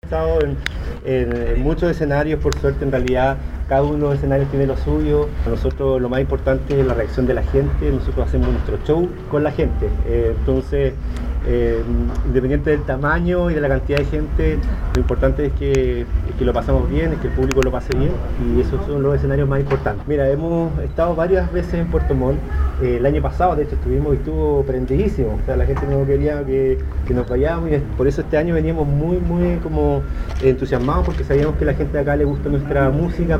Segunda noche del Festival Sentados Frente al Mar tuvo como protagonista a la música tropical para todas las edades - RadioSago